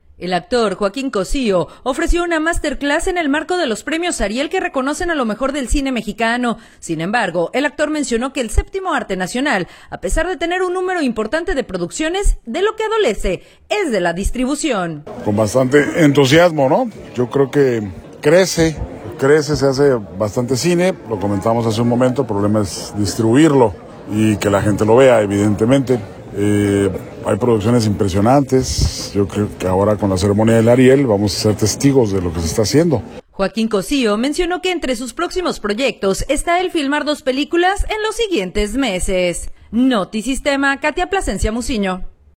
El actor, Joaquín Cosío, ofreció una Master Class, en el marco de los Premios Ariel, que reconocen a lo mejor del cine mexicano. Sin embargo, el actor mencionó que el séptimo arte nacional a pesar de tener un número importante de producciones, de lo que adolece es de la distribución.